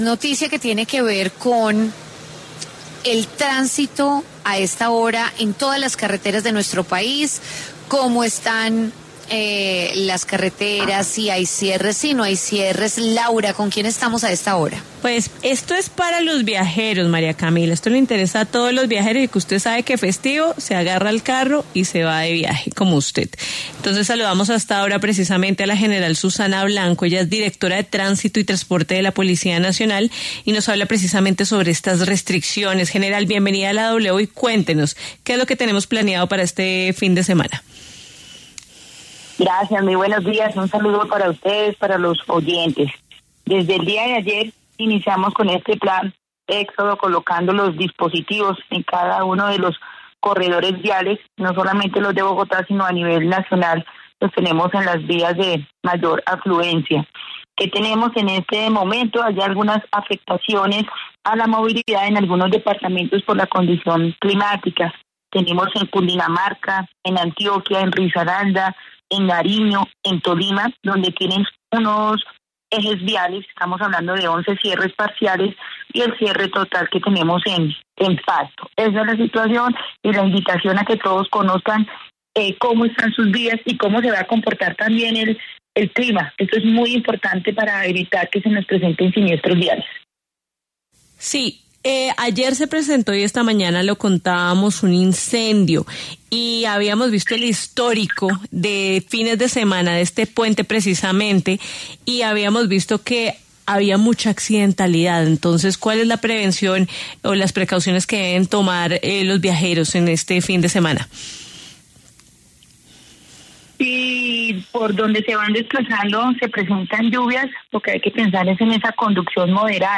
La general Susana Blanco, directora de Tránsito y Transporte de la Policía Nacional, habló en W Fin de Semana acerca de las restricciones de movilidad para este puente festivo en toda Colombia.